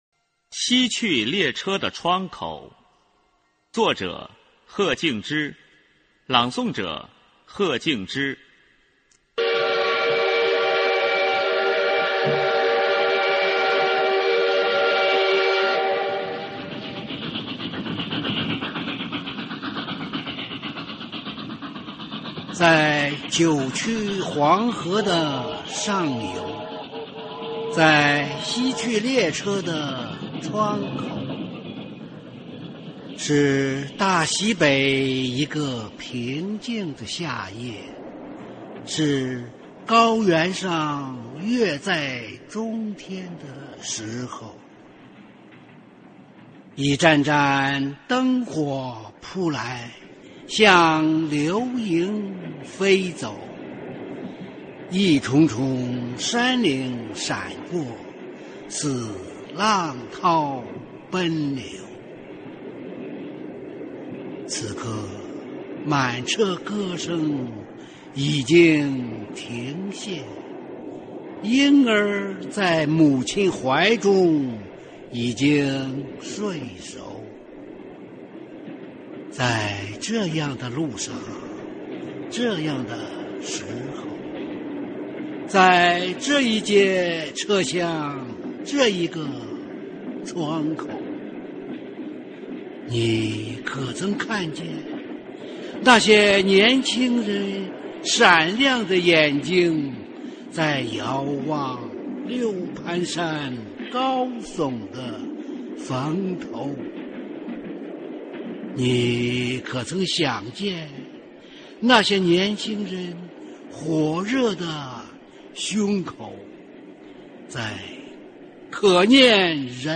[21/4/2009]配乐诗朗诵《西去列车的窗口》